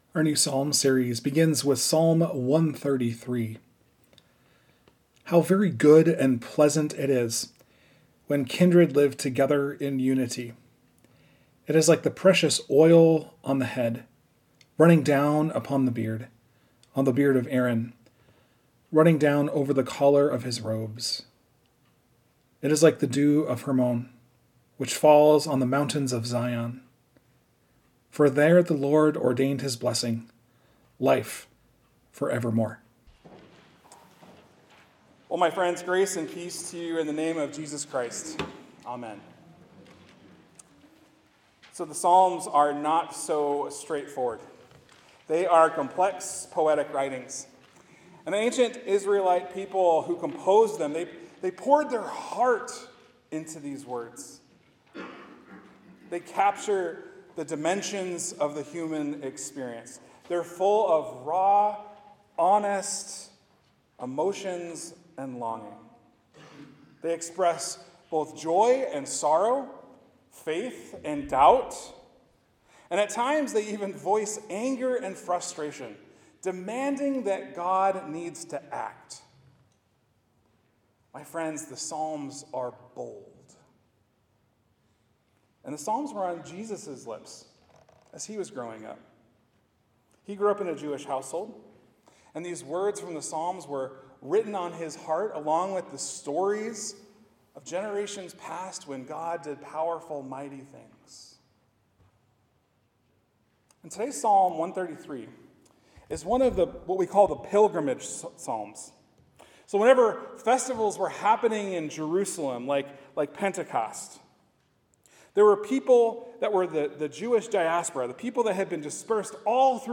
Sermons | Joy Lutheran Church